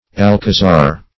Alcazar \Al*ca"zar\, n.